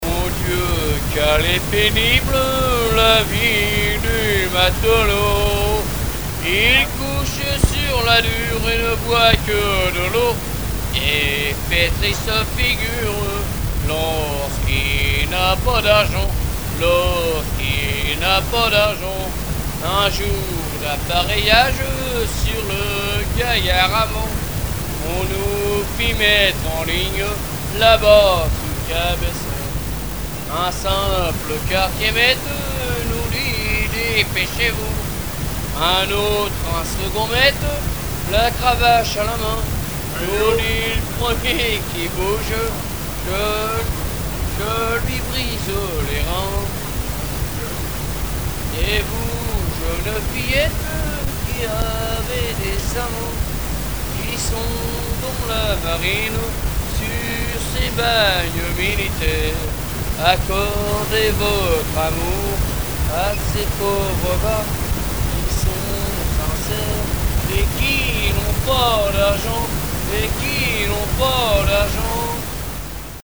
Genre strophique
Chansons traditionnelles et témoignages
Pièce musicale inédite